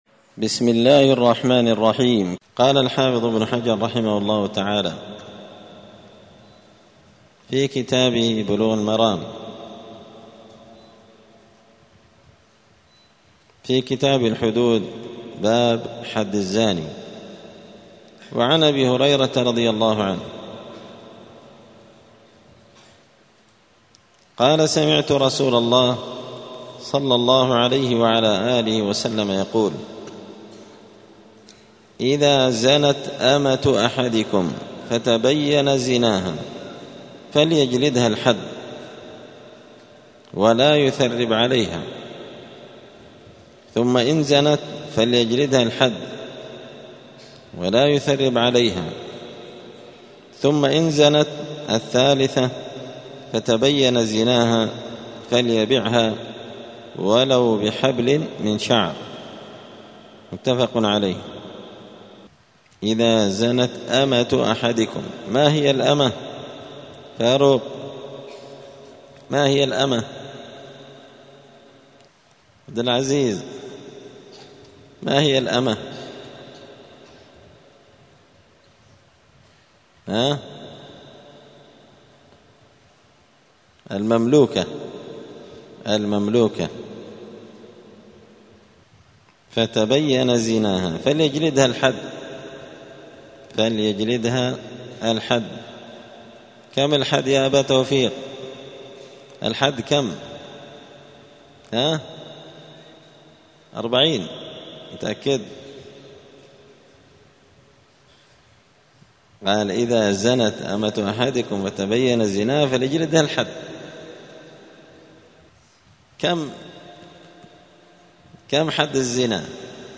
*الدرس السادس (6) {باب حد الأمة إذا زنت}*